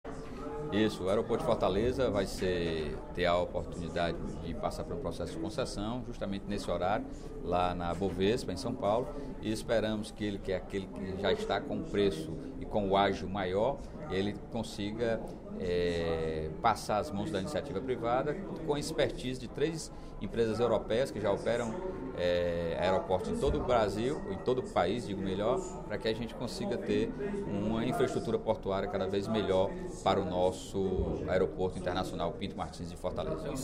O deputado Sérgio Aguiar (PDT) ressaltou, nesta quinta-feira (16/03), durante o primeiro expediente da sessão plenária, a realização de leilão que deverá conceder o Aeroporto Internacional Pinto Martins à iniciativa privada.